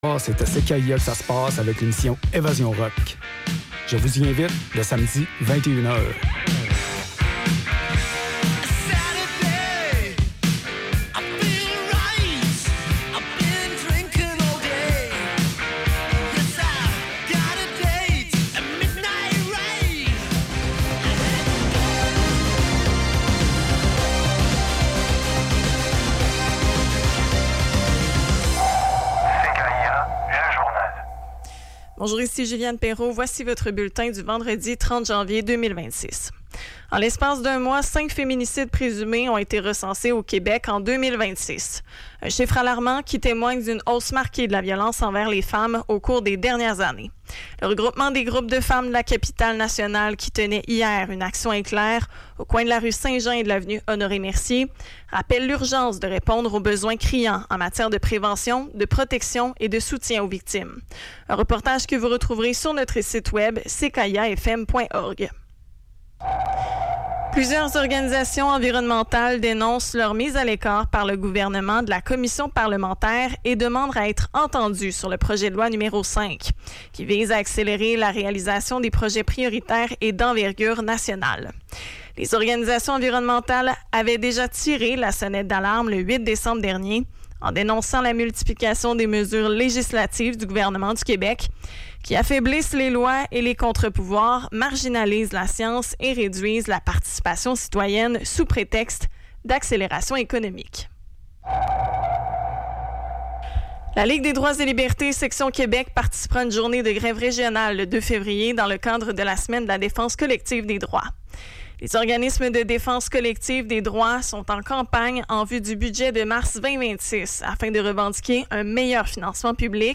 Revue de presse liée à l’actualité, aux changements climatiques et à la surconsommation.